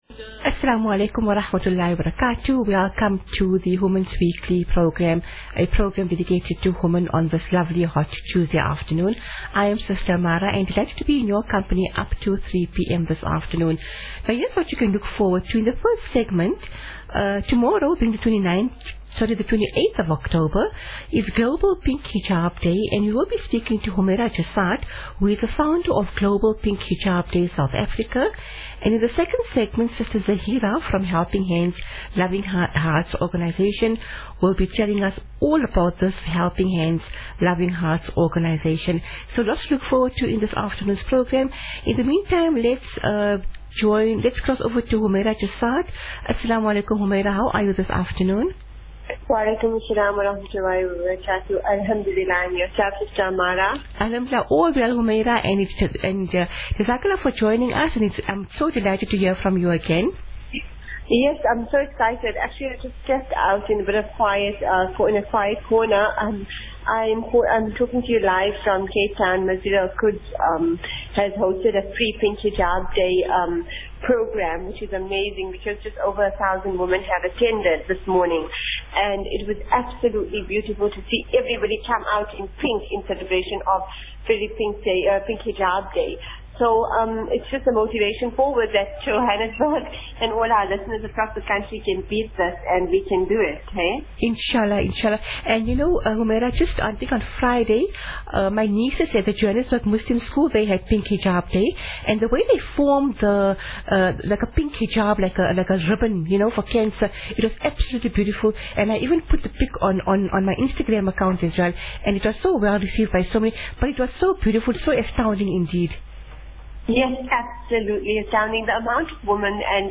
Pink Hijab Day + interview with Helping Hands Loving Hearts Organisation